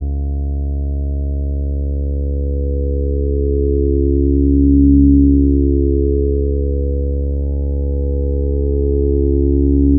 This script converts a didgeridoo bore into a wav file.
Here is an example of the generated audio. The "player" morphes through the vowels a -> e -> i -> o -> u.